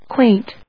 /kwéɪnt(米国英語), kweɪnt(英国英語)/